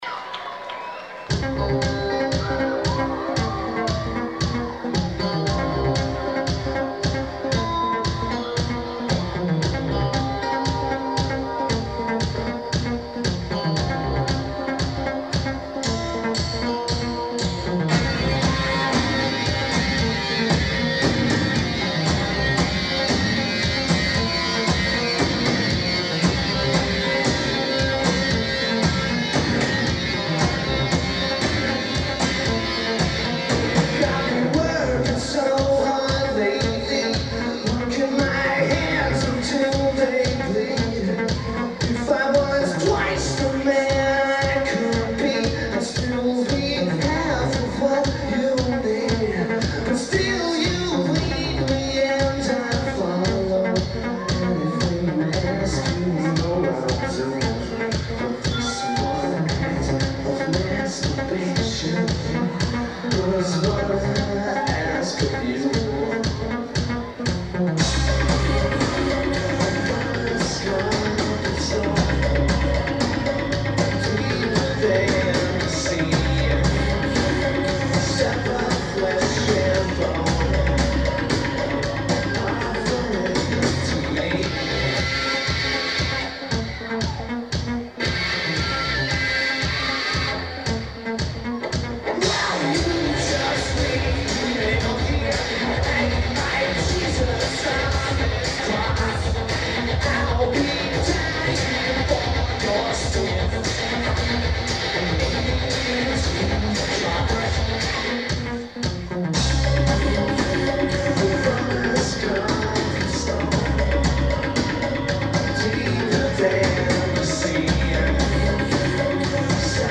Cabaret Metro